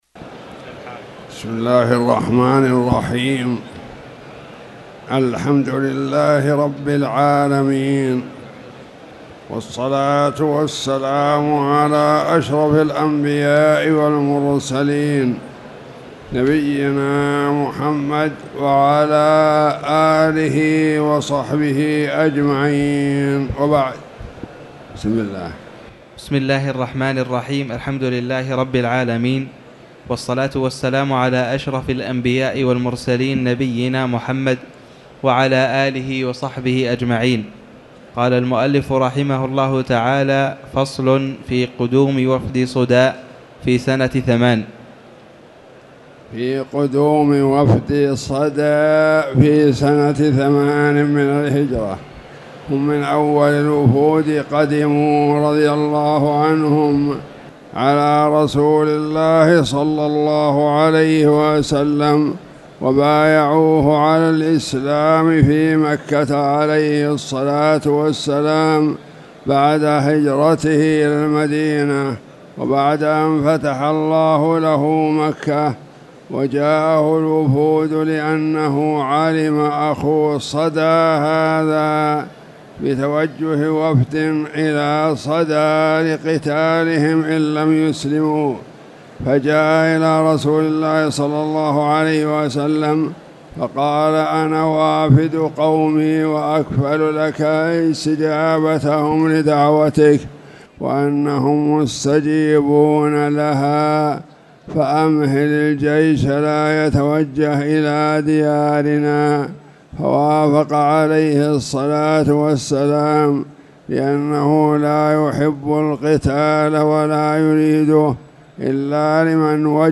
تاريخ النشر ١٦ شعبان ١٤٣٨ هـ المكان: المسجد الحرام الشيخ